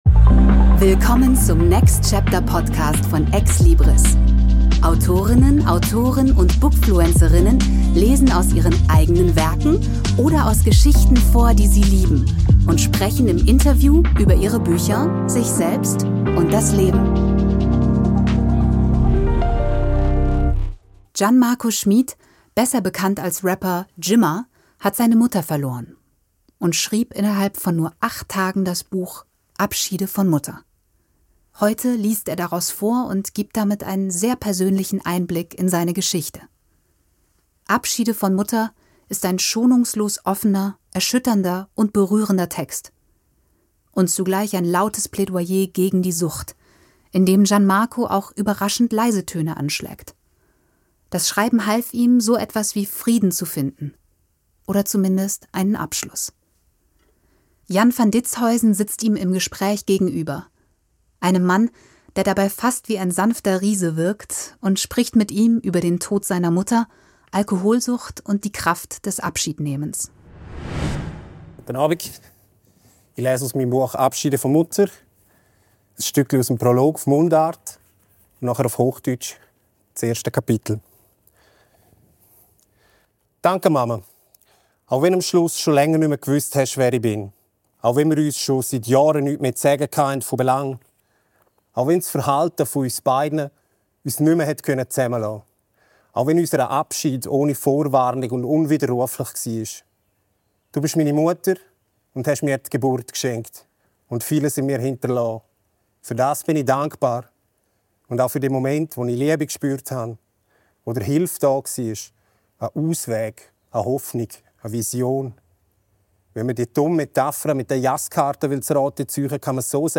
Gian-Marco Schmid, besser bekannt als Rapper Gimma, hat seine Mutter verloren - und schrieb innerhalb von nur acht Tagen das Buch «Abschiede von Mutter». Heute liest er daraus vor und gibt damit einen sehr persönlichen Einblick in seine Geschichte.